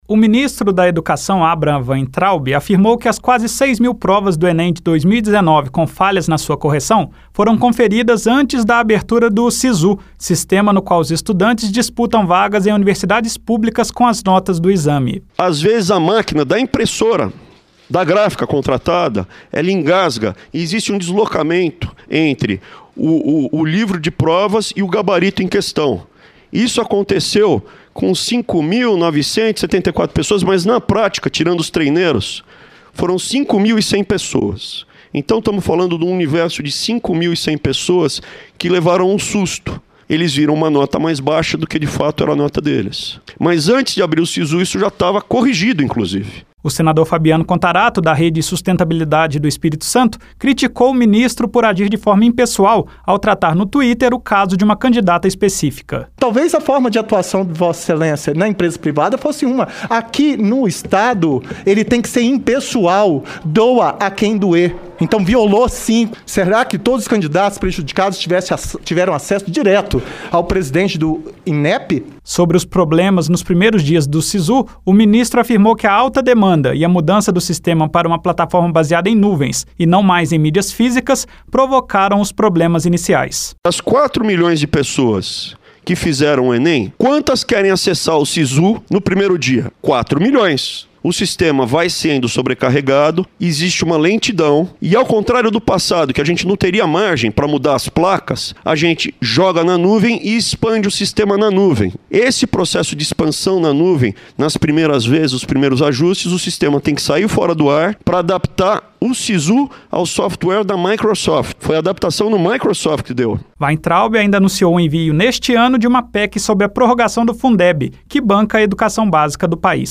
O ministro da educação, Abraham Weintraub, afirmou em audiência pública na Comissão de Educação (CE) que os erros nos gabaritos de provas do Enem de 2019 foram corrigidos antes da abertura do SiSU e não prejudicaram a disputa de vagas pelos estudantes nas universidades públicas.